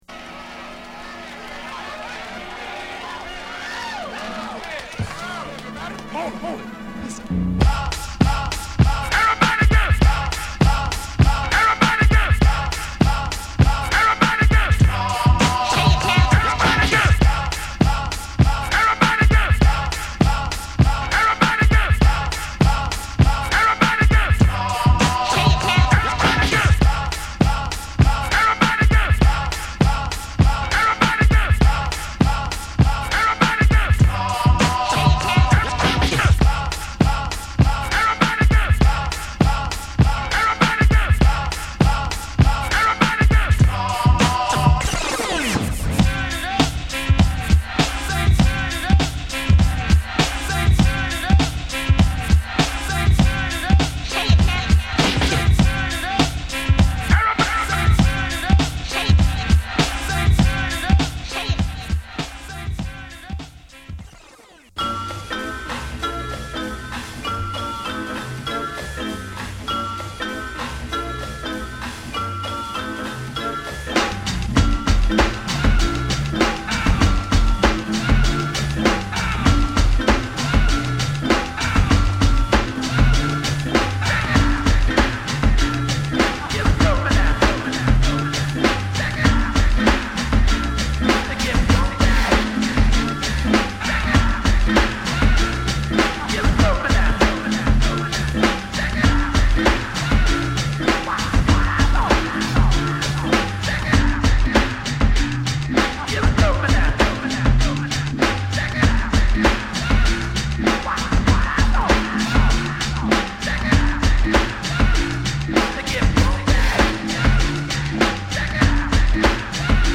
定番ブレイクに定番声ネタを存分に使用。